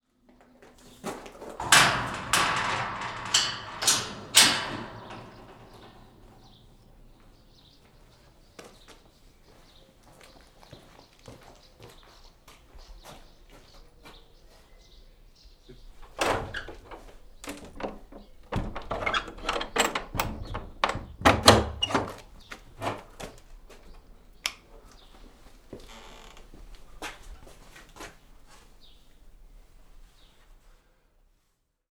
Toutefois, voici quelques exemples anonymes de sons qui ont accompagné un témoignage.
Grille.wav